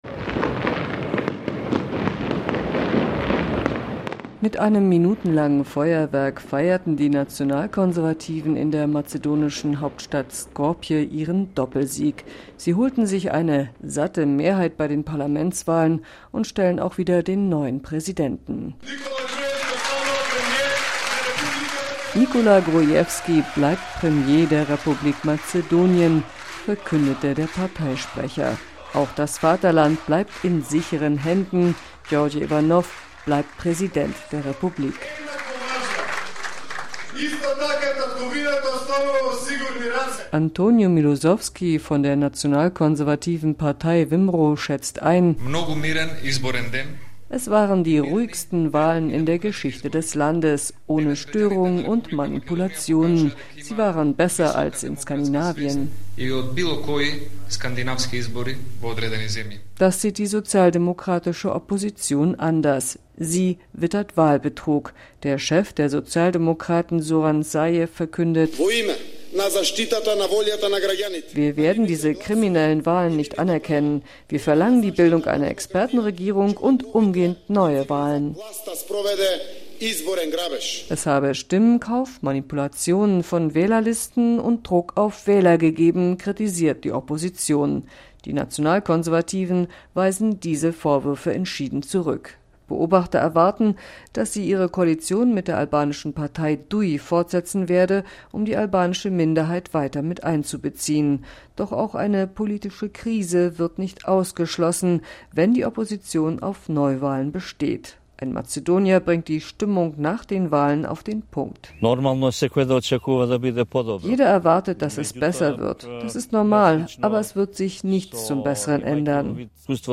berichtet aus Skopje.